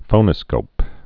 (fōnə-skōp)